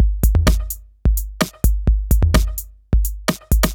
Index of /VEE/VEE2 Loops 128BPM
VEE2 Electro Loop 070.wav